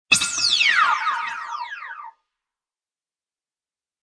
Descarga de Sonidos mp3 Gratis: videojuegos 1.